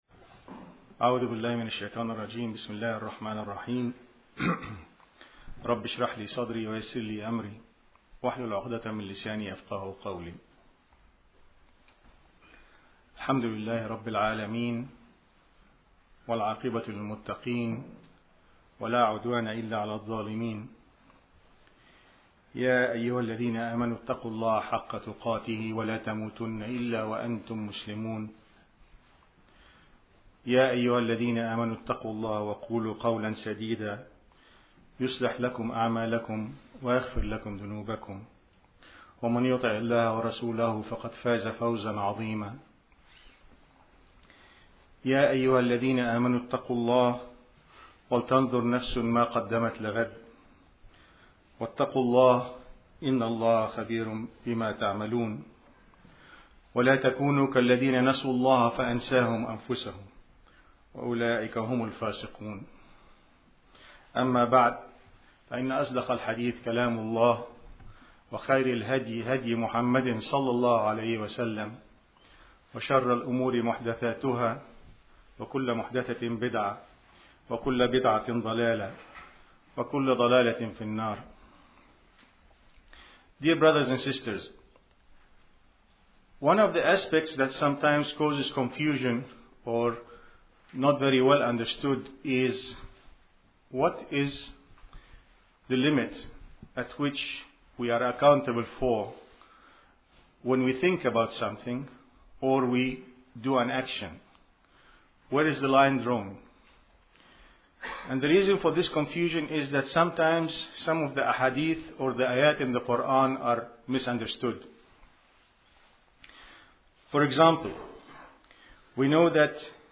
To hear this audio khutbah, please click here: Will Allah punish bad thoughts